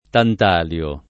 tantalio [ tant # l L o ]